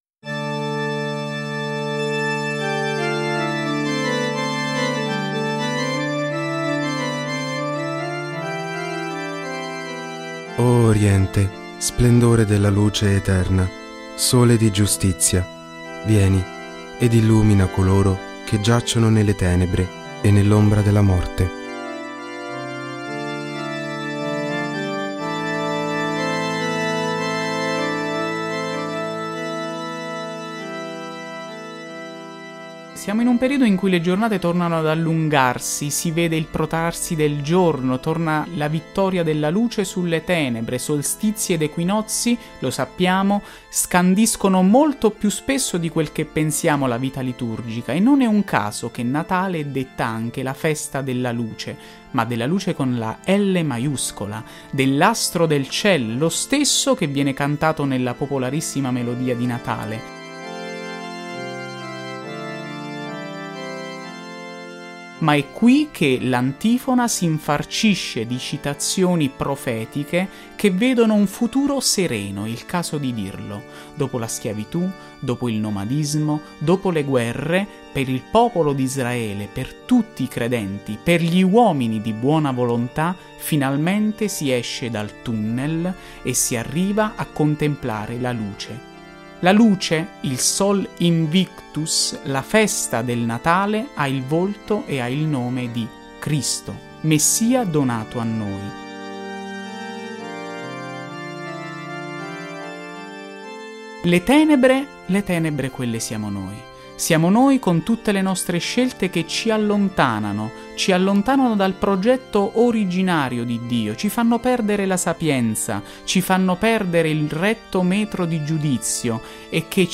Le esecuzioni delle Antifone O dell'"Ensemble dei Fiorentini"
O-ORIENS-CON-EFFETTI.mp3